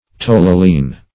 Search Result for " tolylene" : The Collaborative International Dictionary of English v.0.48: Tolylene \Tol"yl*ene\, n. (Chem.)